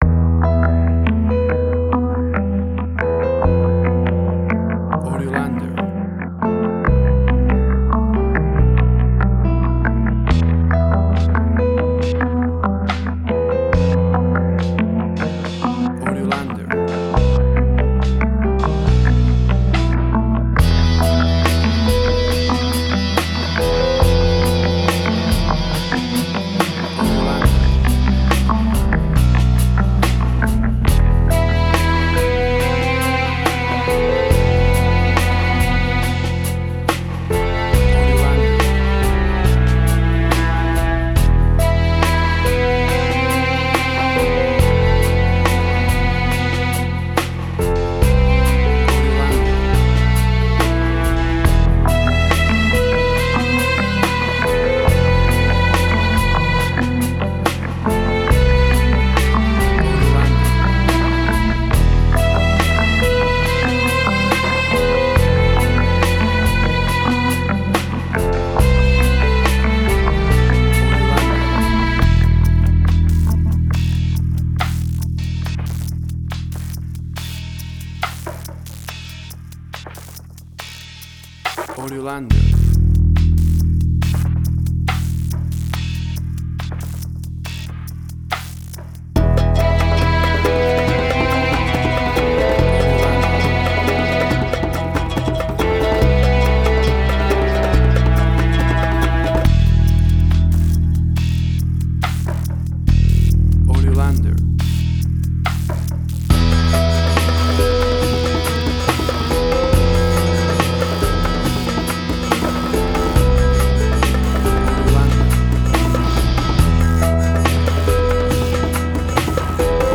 Trip Hop
emotional music
Tempo (BPM): 70